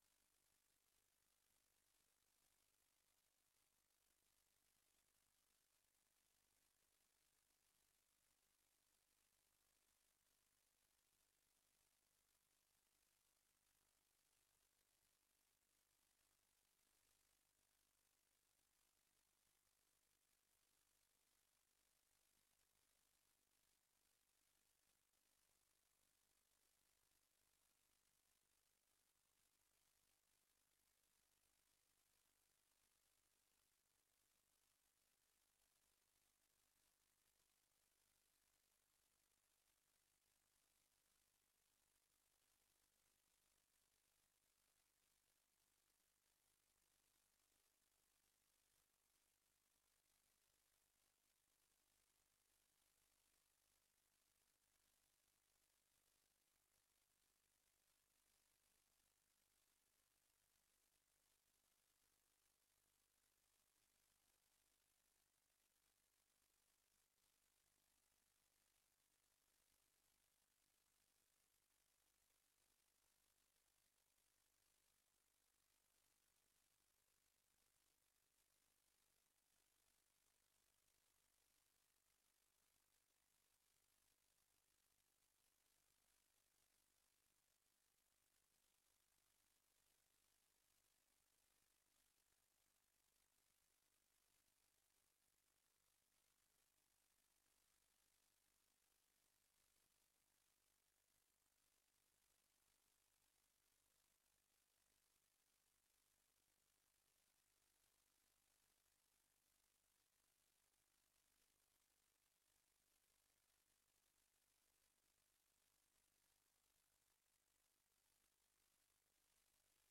Raads-info-bijeenkomst 03 april 2025 20:00:00, Gemeente Oude IJsselstreek
Download de volledige audio van deze vergadering
Locatie: DRU Industriepark - Conferentiezaal